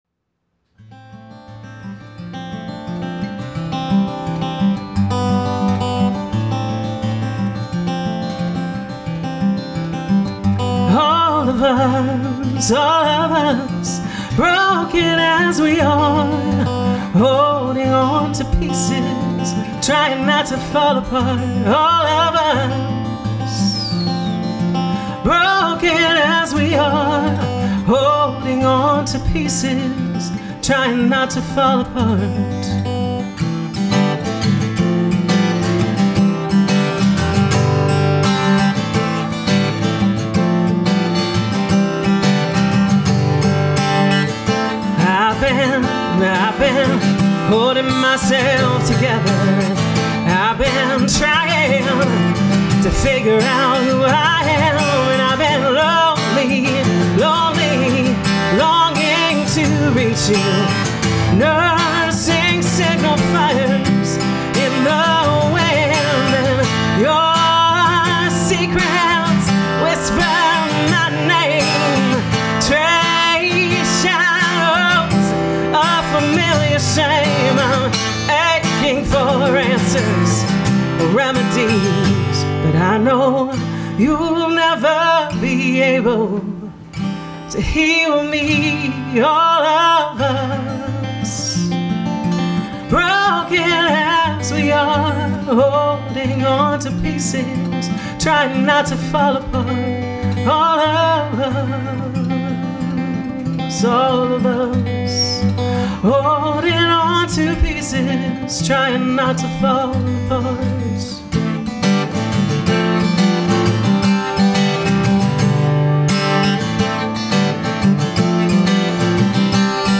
stripped-back musical arrangement